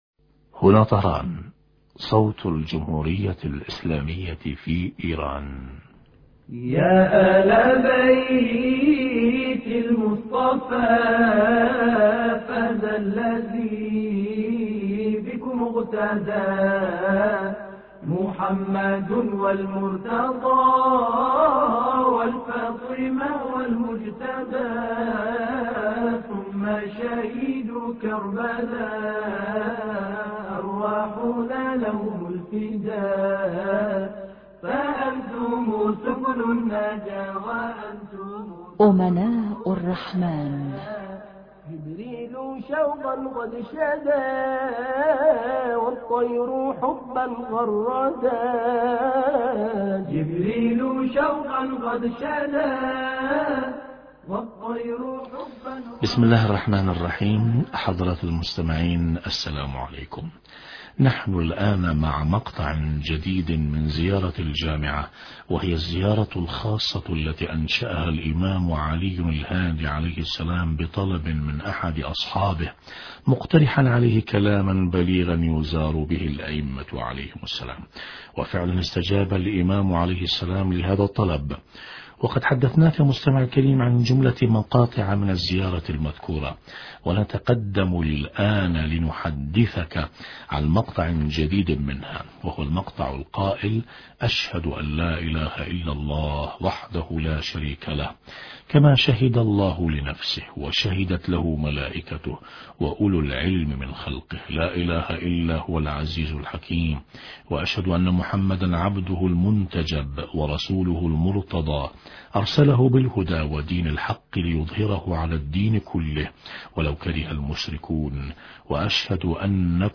الترابط بين الشهادات الثلاث (بالتوحيد والنبوة والامامة) حوار